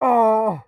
Play, download and share Myers pallet 2 slam original sound button!!!!
myers-pallet-2-slam.mp3